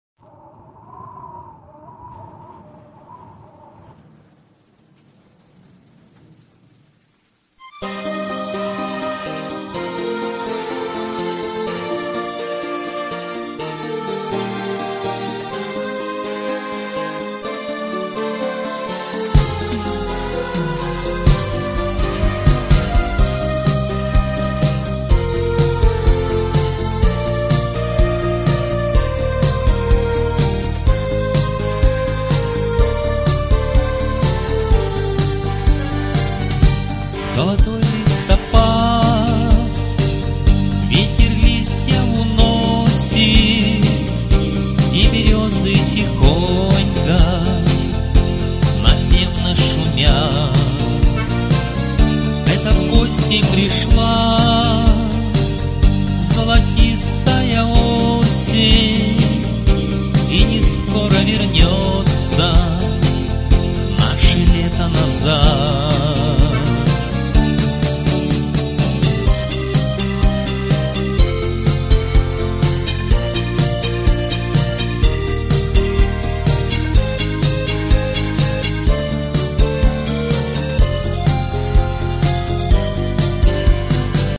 Демо-версии наших песен